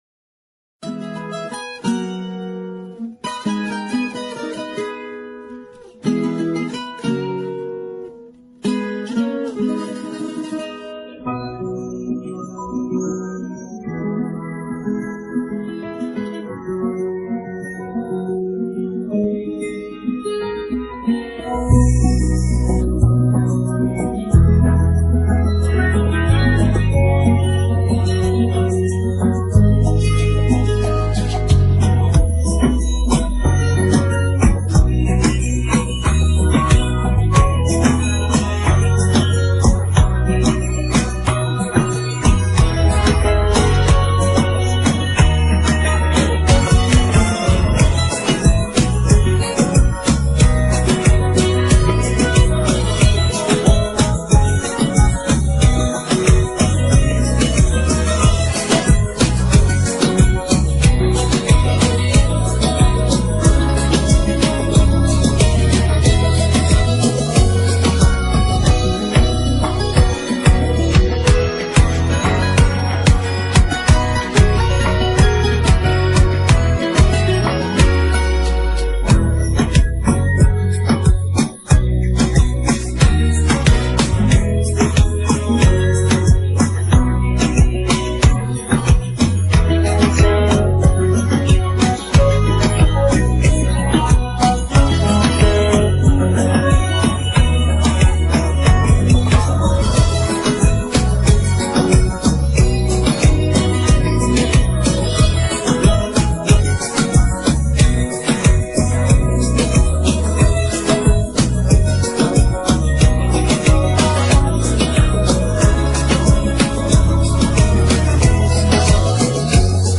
La cançó instrumental